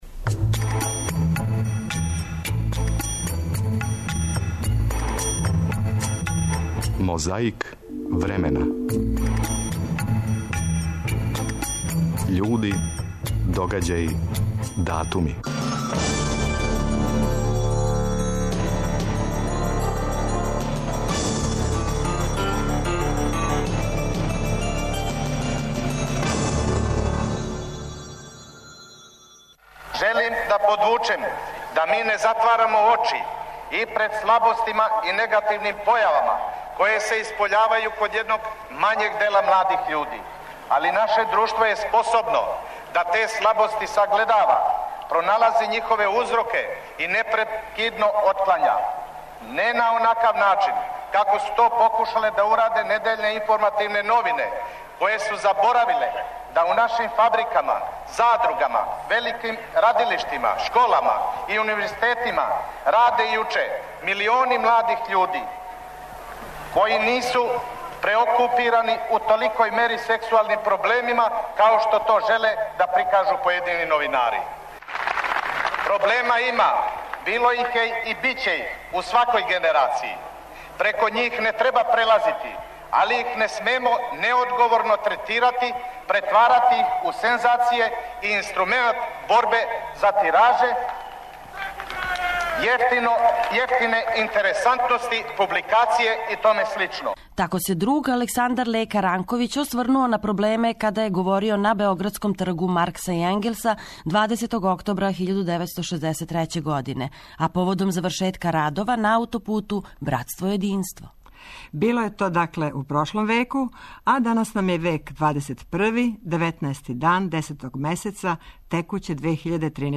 Помажу нам снимци из Тонског архива Радио Београда.